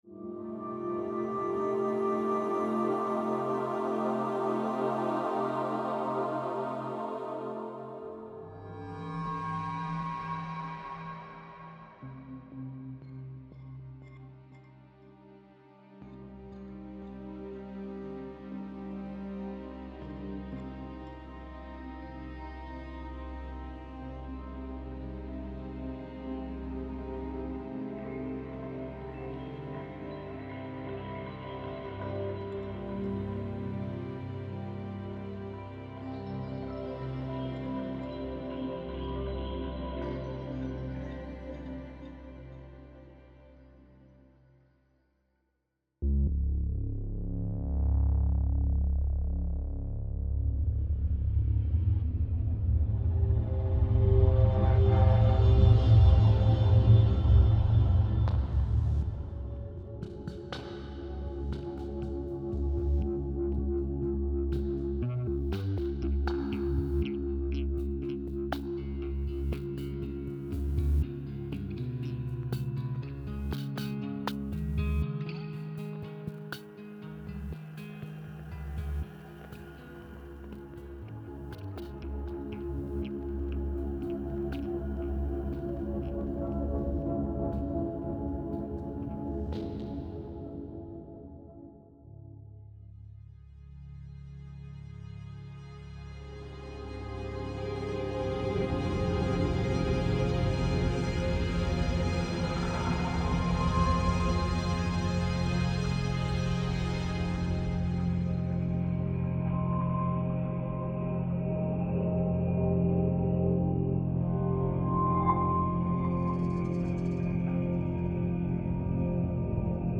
layered ambient environments